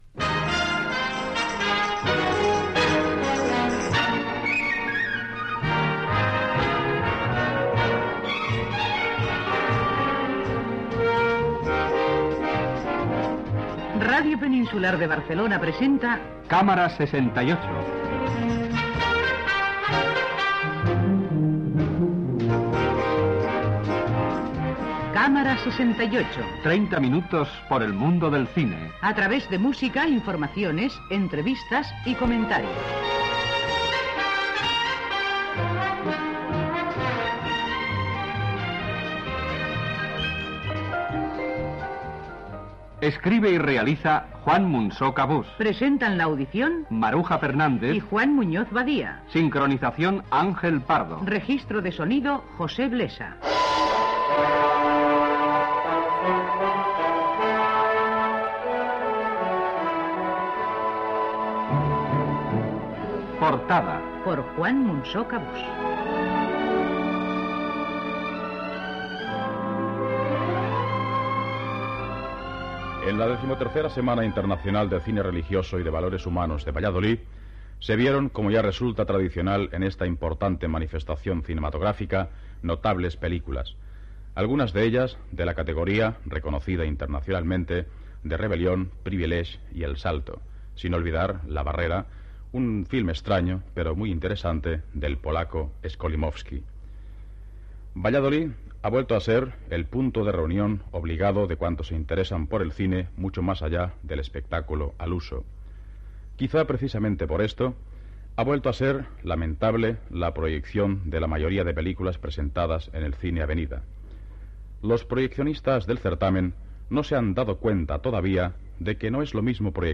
Careta del programa i secció "Portada" dedicada a la XIII Semana de Cine Religioso de Valladolid